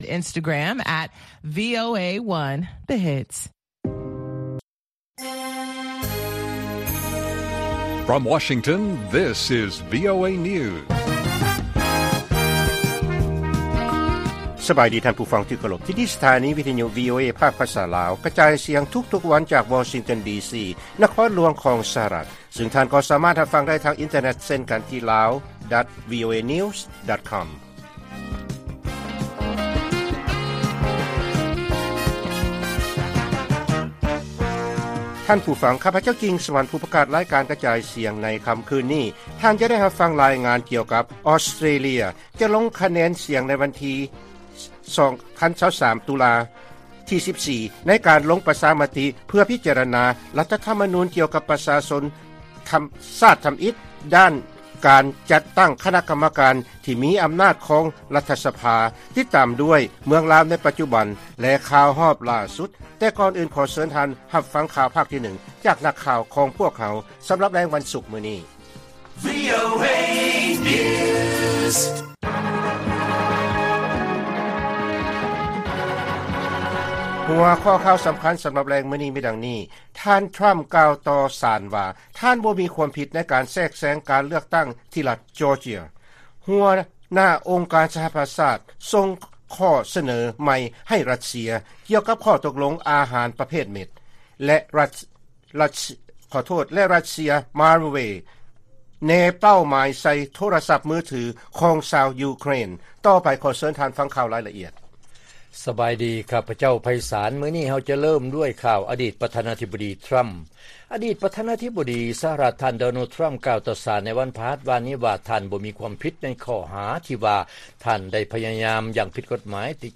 ລາຍການກະຈາຍສຽງຂອງວີໂອເອ ລາວ: ທ່ານທຣຳກ່າວຕໍ່ສານວ່າ ທ່ານບໍ່ມີຄວາມຜິດ ໃນການແຊກແຊງການເລືອກຕັ້ງທີ່ລັດຈໍເຈຍ.